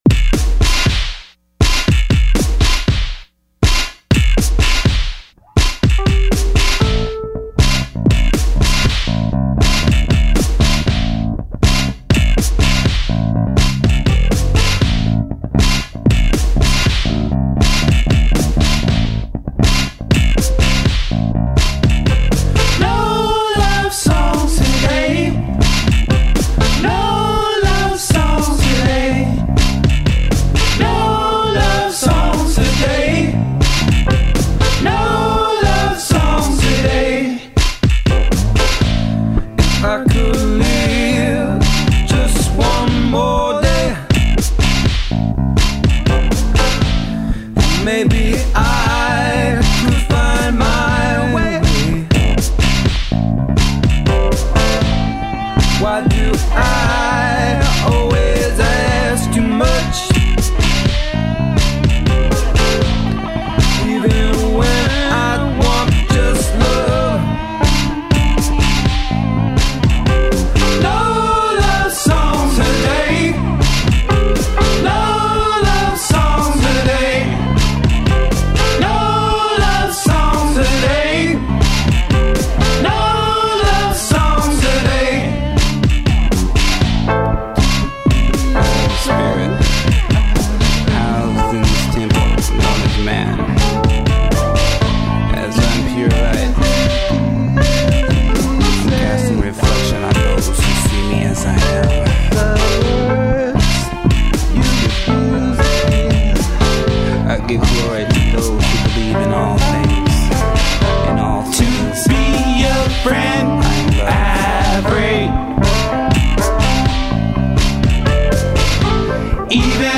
Additional vocals
Guitar
Bass and Keyboards
Drum Programming